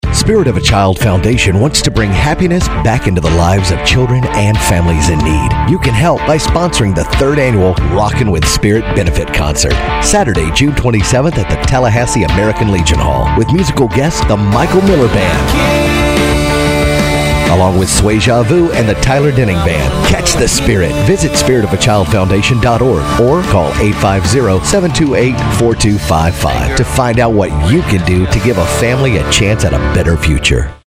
Cumulus Radio Commercial